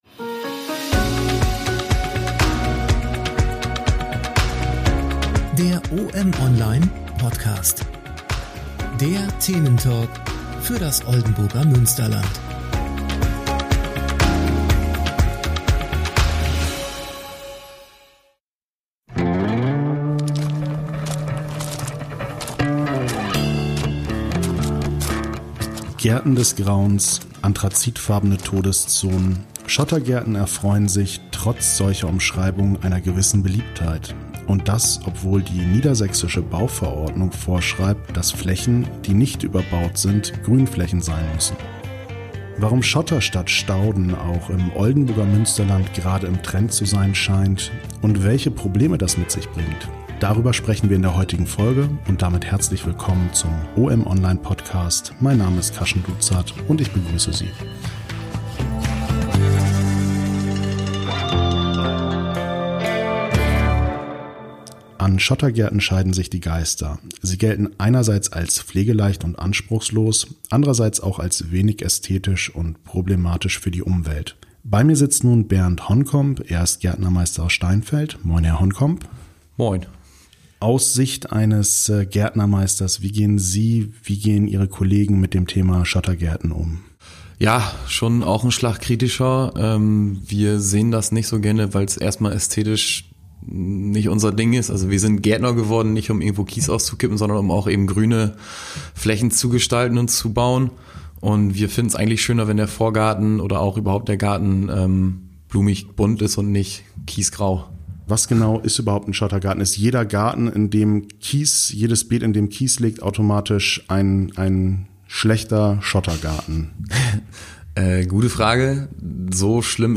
Beschreibung vor 4 Jahren Der Thementalk für das Oldenburger Münsterland: In der 9. Folge geht es um die sogenannten "Gärten des Grauens" - damit gemeint sind die in Kritik gekommenen Schottergärten.